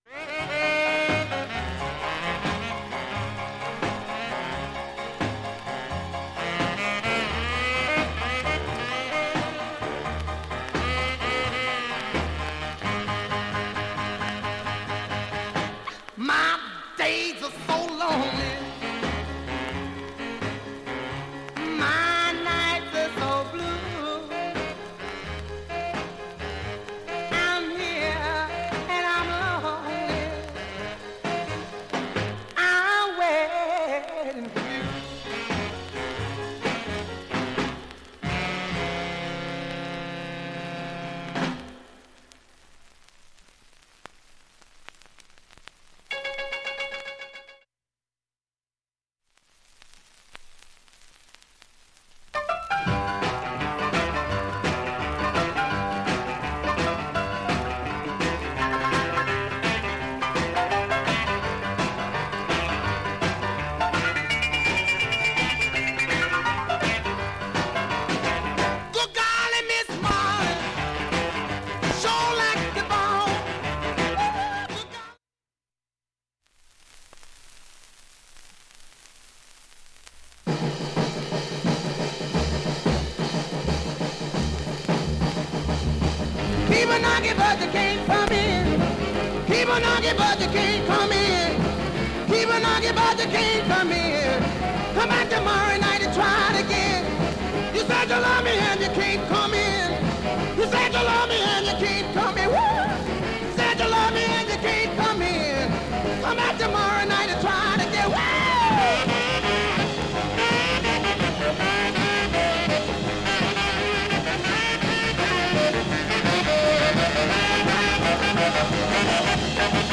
SHURE M 44G 針圧３グラムで
針飛びはありませんでした。
1,A-3終盤にかすかなプツが14回出ます。
2,(49s〜)B-1序盤にかすかな２回プツ。
◆ＵＳＡ盤オリジナルMono